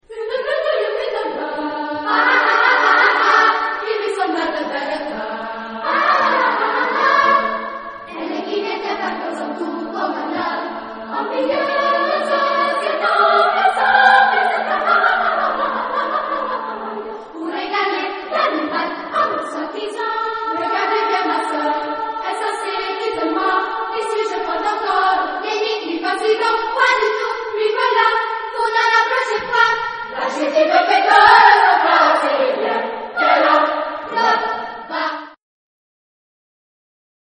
Chœur ; Profane ; contemporain
SSA (3 voix égales de femmes )
Tonalité : ré majeur